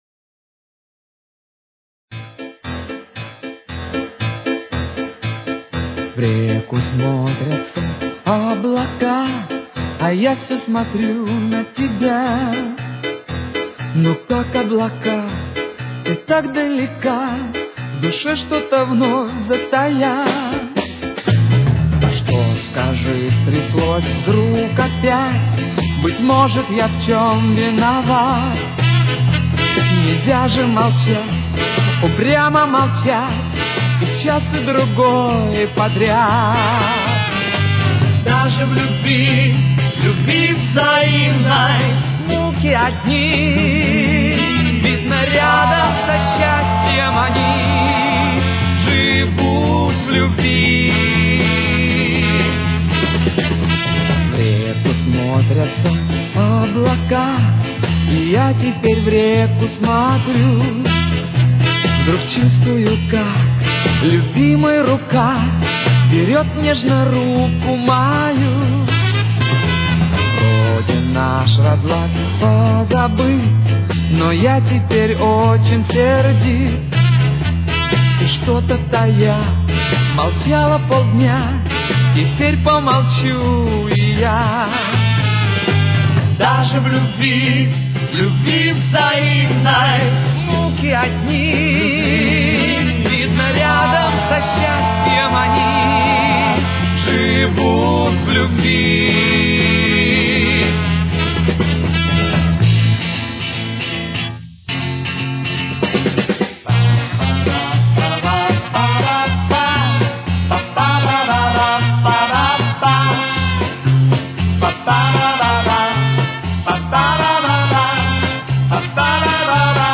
с очень низким качеством (16 – 32 кБит/с)
Тональность: Ля минор. Темп: 123.